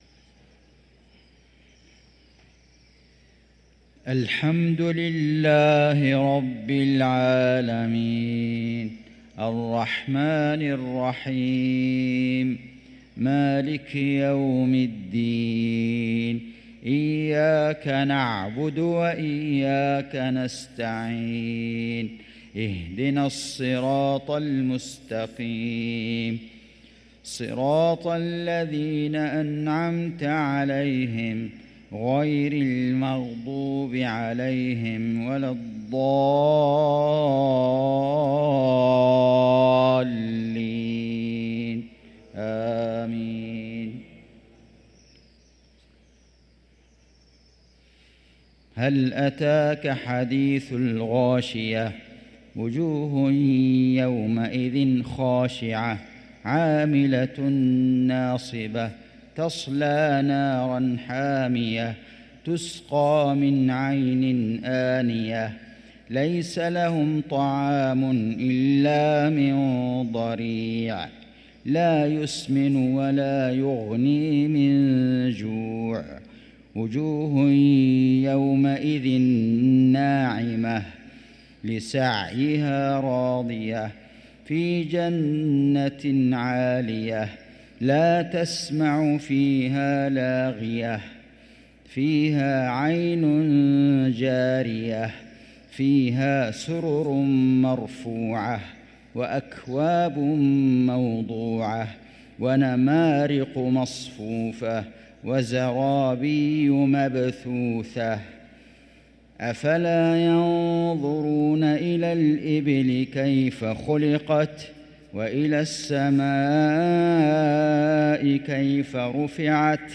صلاة العشاء للقارئ فيصل غزاوي 5 رجب 1445 هـ
تِلَاوَات الْحَرَمَيْن .